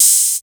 Tr8 Open Hat 02.wav